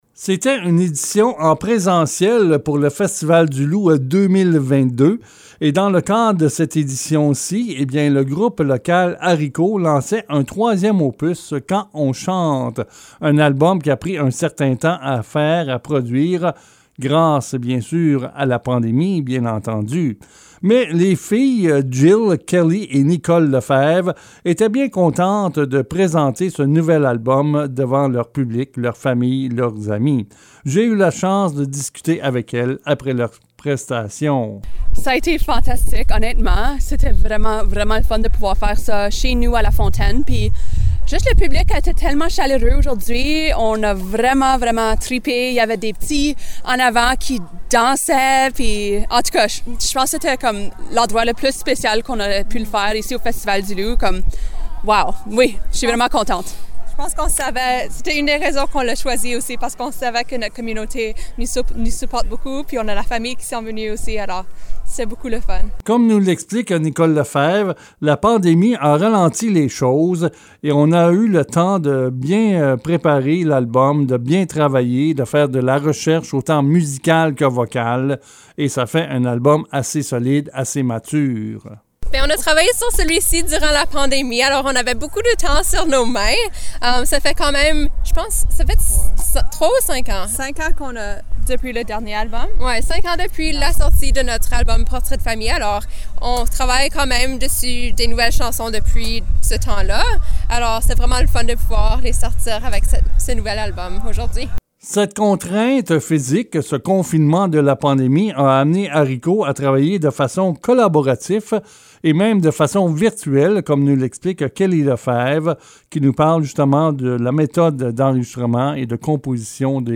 Le reportage
ENTRV-Ariko-Quand-on-chante-final.mp3